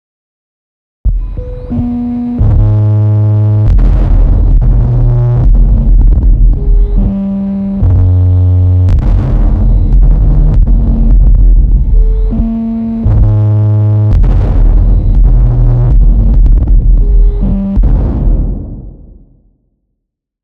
При разных значениях этого параметра можно получать довольно разное звучание на одних и тех же настройках: